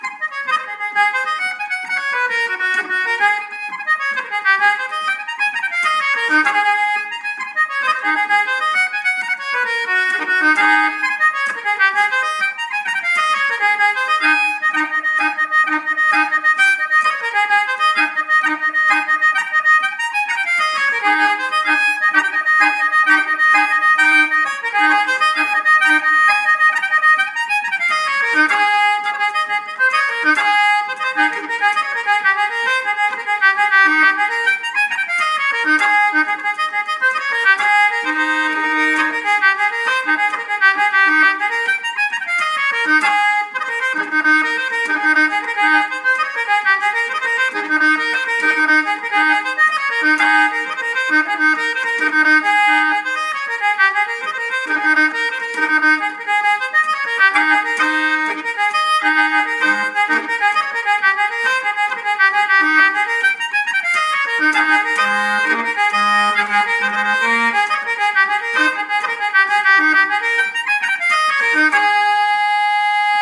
Concertina